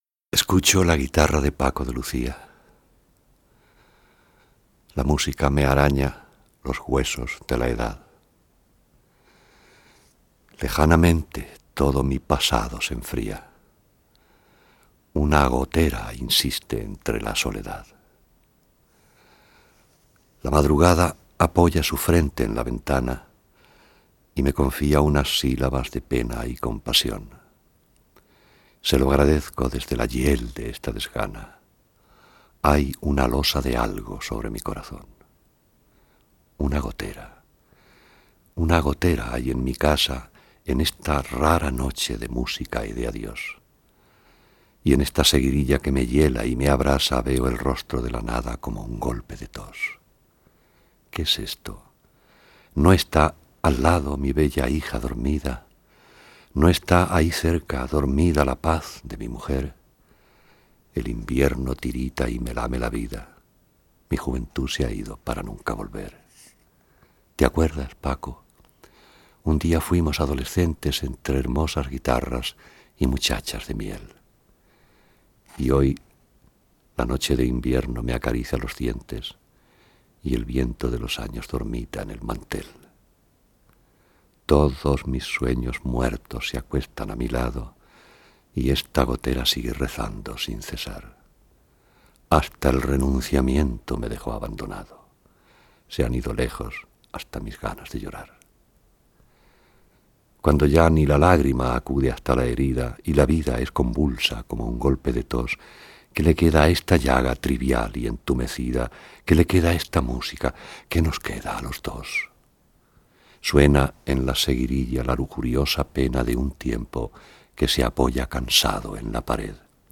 This poem, Una Gotera by Félix Grande, read by the poet himself, is dedicated to Paco de Lucía.